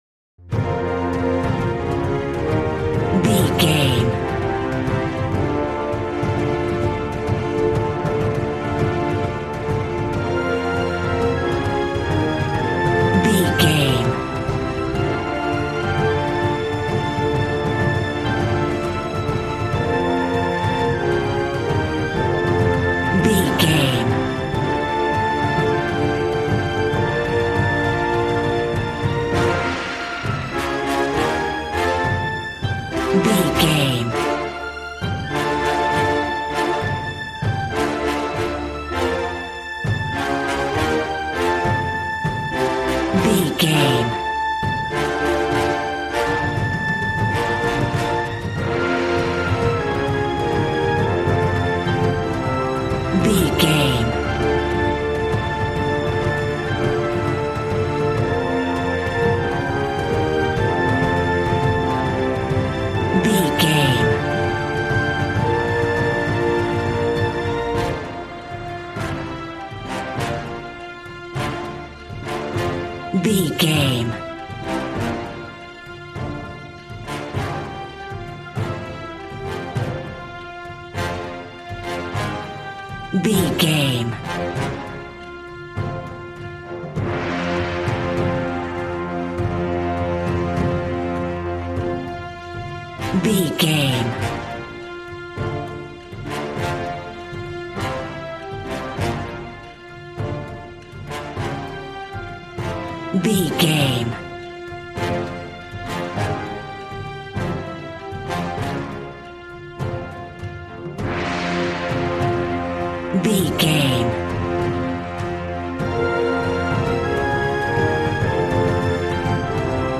Action and Fantasy music for an epic dramatic world!
Ionian/Major
hard
groovy
drums
bass guitar
electric guitar